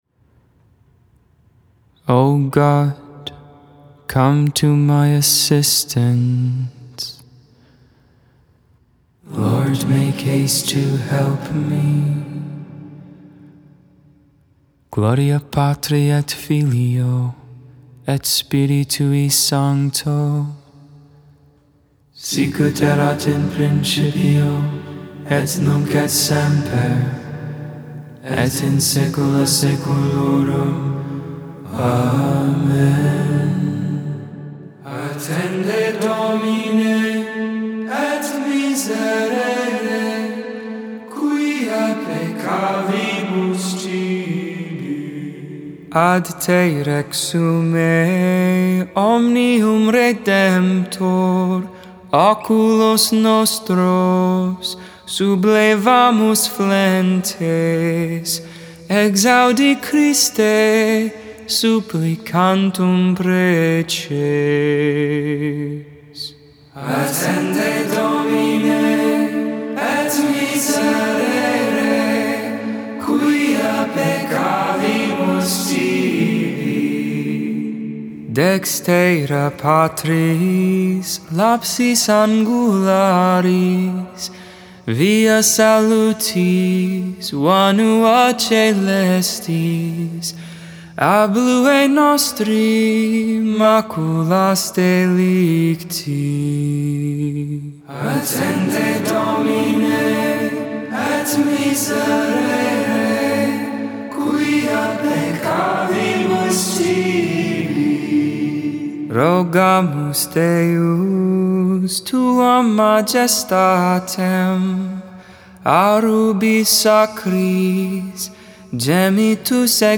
The Liturgy of the Hours: Sing the Hours 4.4.22 Lauds, Monday Morning Prayer Apr 03 2022 | 00:16:57 1x 00:00 / 00:16:57 Subscribe Share Spotify RSS Feed Share Link Embed